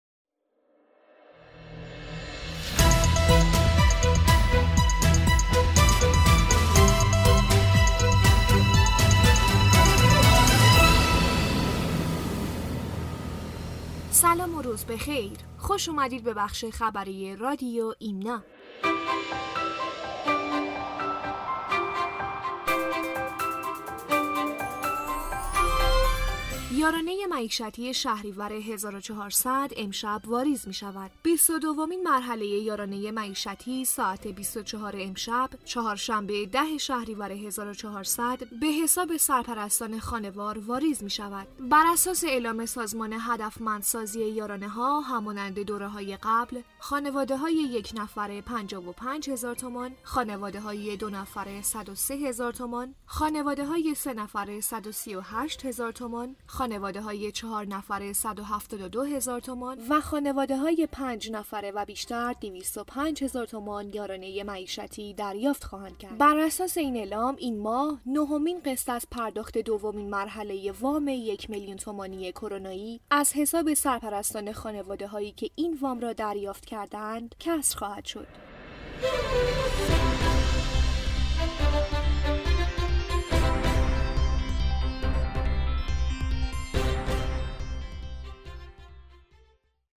بسته خبری رادیو ایمنا/